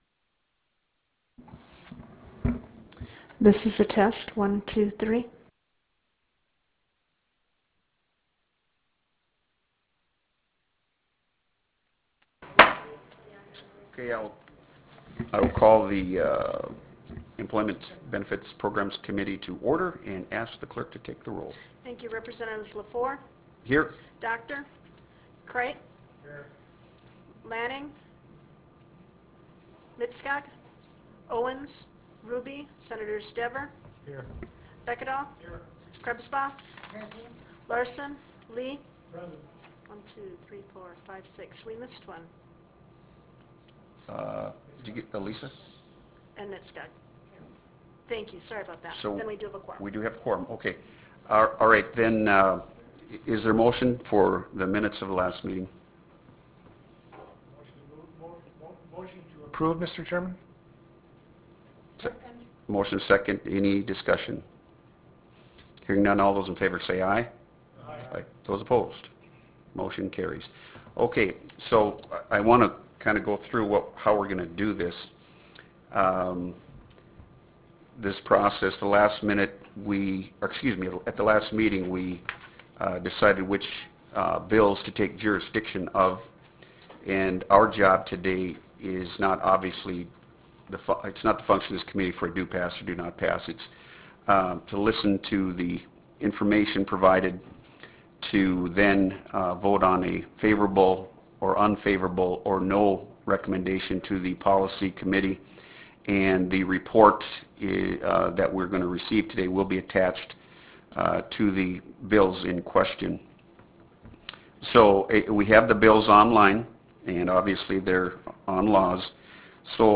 Pioneer Room State Capitol Bismarck, ND United States
Meeting Audio